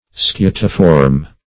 Search Result for " scutiform" : The Collaborative International Dictionary of English v.0.48: Scutiform \Scu"ti*form\, a. [L. scutum shield + -form: cf. F. scutiforme.]